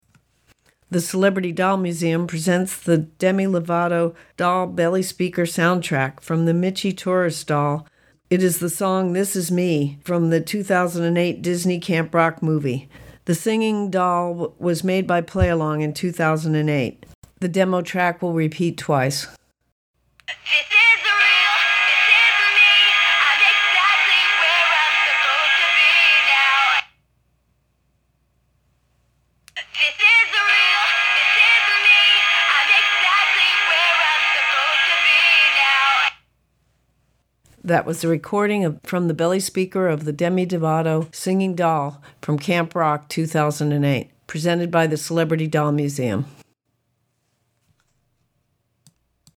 are from the belly speaker of the doll